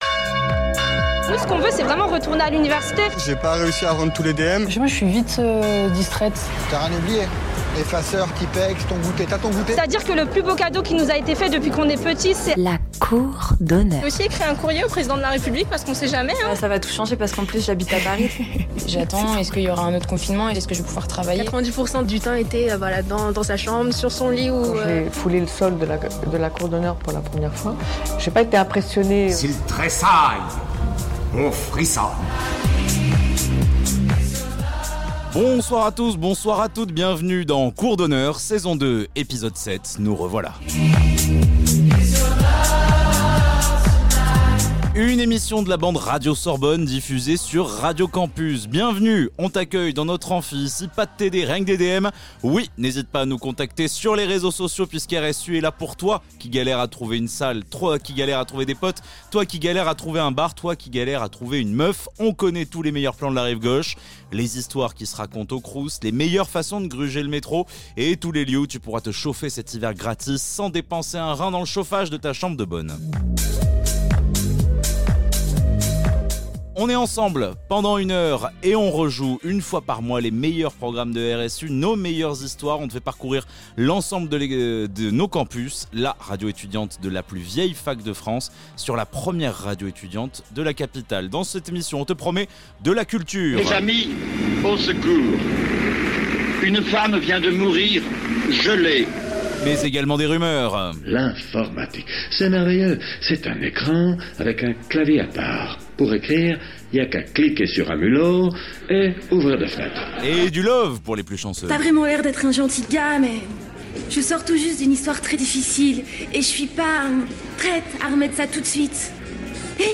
Les étudiants de Radio Sorbonne Université piratent Radio Campus Paris